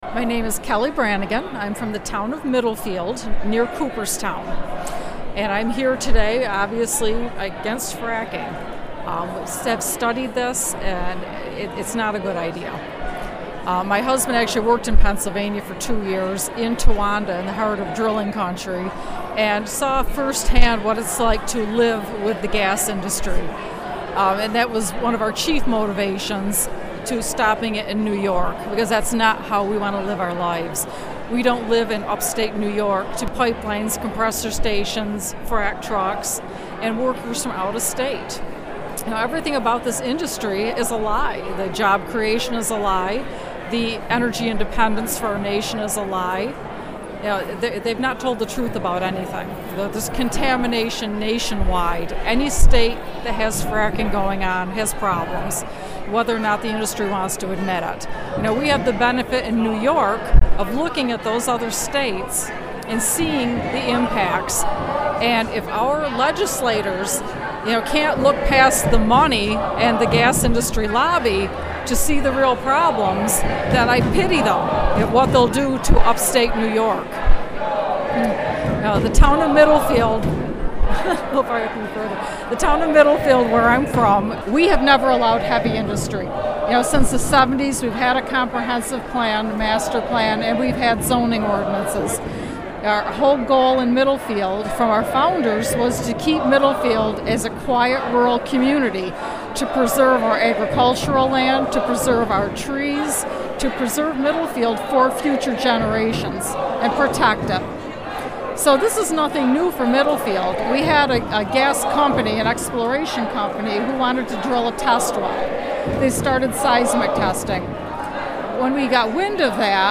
Interview outside "State of the State" address in Albany.